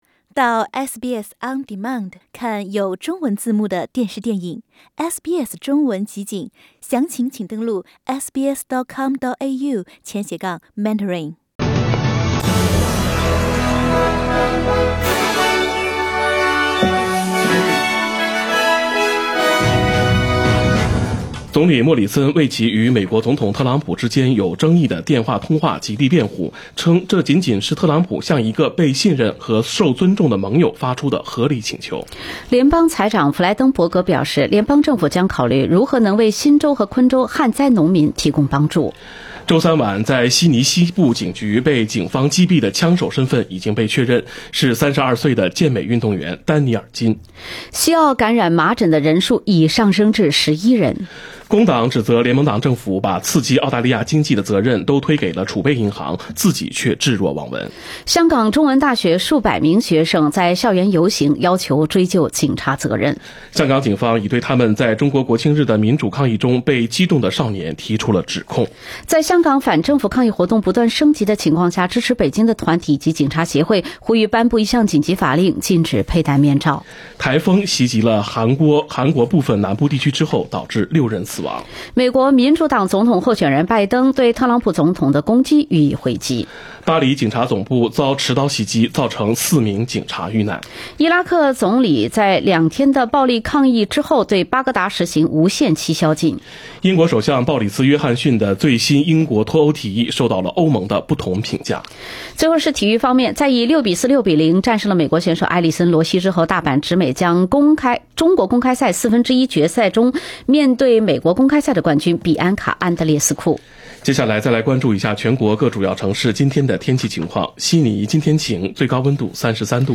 SBS 早新闻（10月4日）